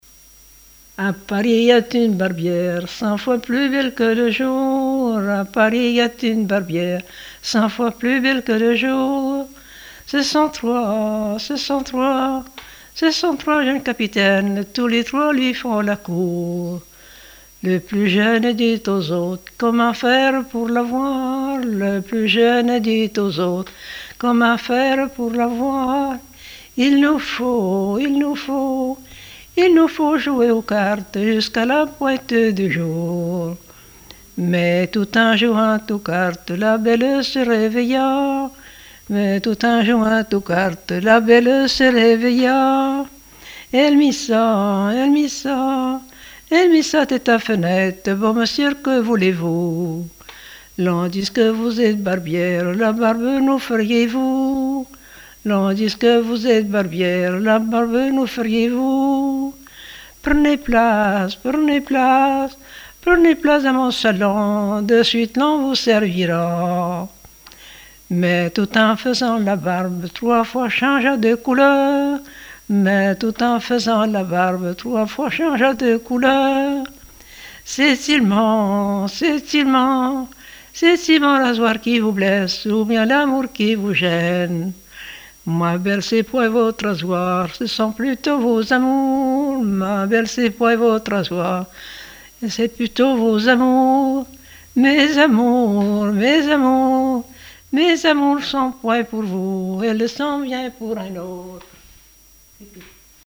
Genre laisse
Enquête sur les chansons populaires
Pièce musicale inédite